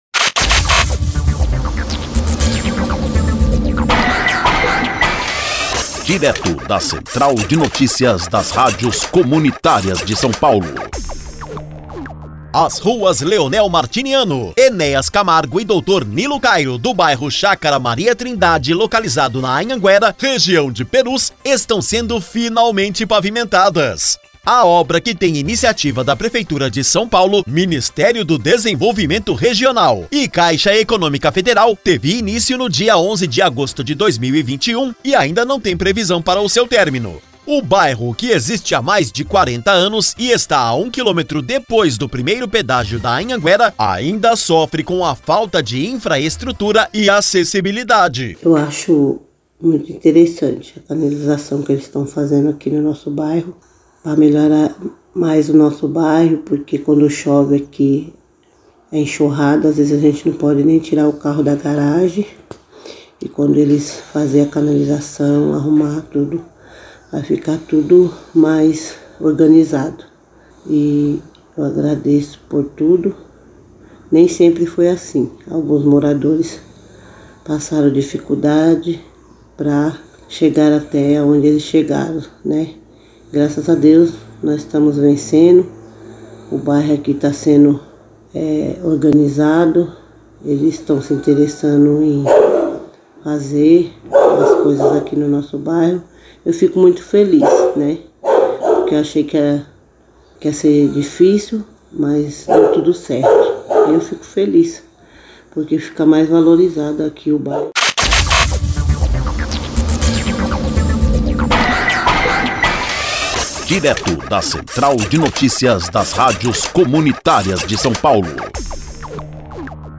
Informativo: PAVIMENTAÇÃO DE VIAS EM PERUS